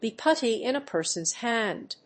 アクセントbe pútty in a person's hánd